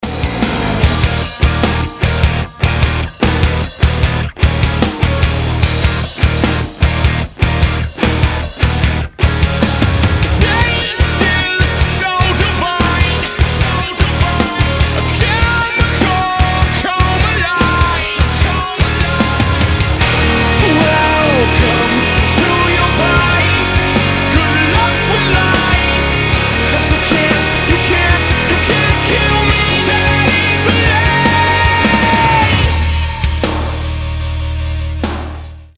мужской вокал
громкие
Hard rock
vocal